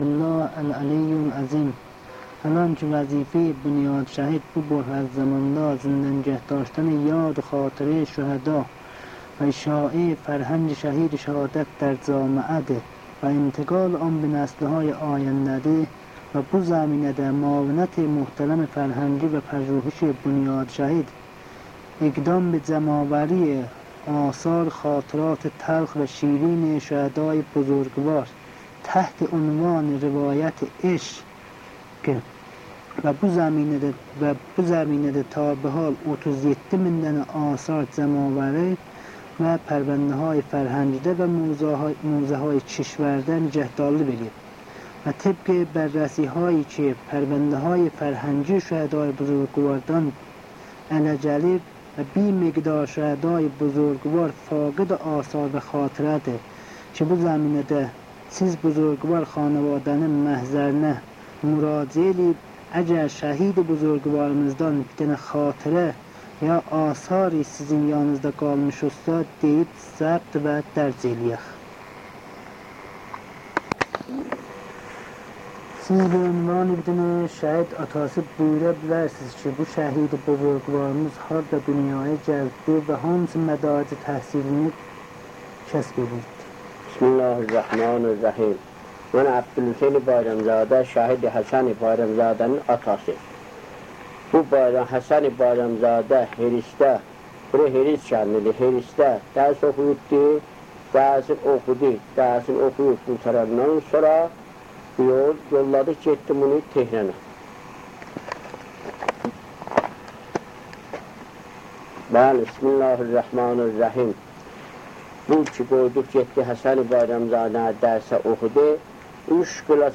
صوت / مصاحبه